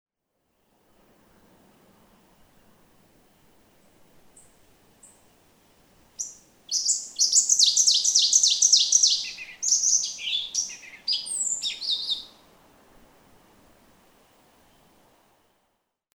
Ovenbird
From the same ovenbird, an hour before sunrise, a dramatic aerial song flight.
Daughters of the American Revolution State Forest, Goshen, Massachusetts.
535_Ovenbird.mp3